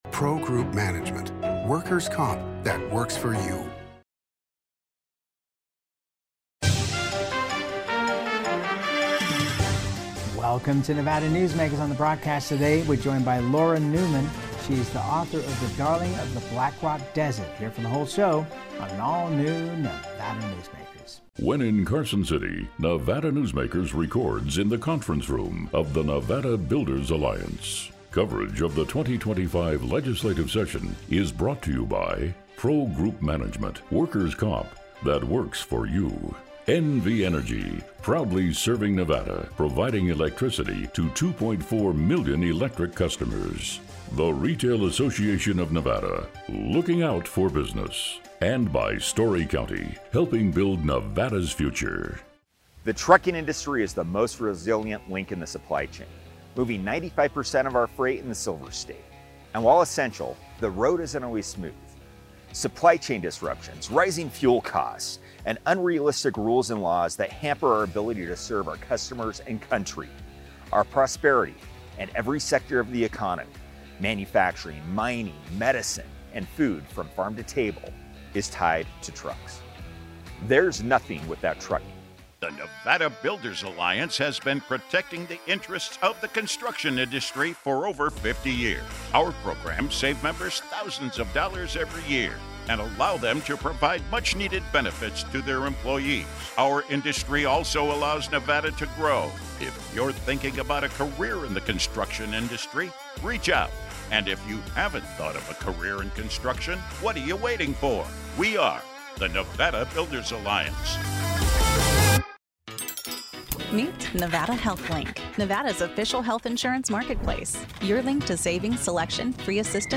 Nevada Newsmakers is a non-partisan statewide news broadcast that features discussions with and about the people and events that concern Nevada.